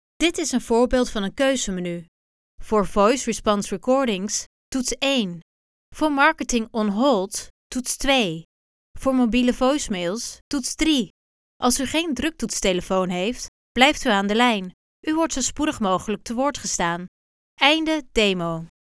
Beluister hier enkele voorbeelden van onze professionele voice over stemartiesten.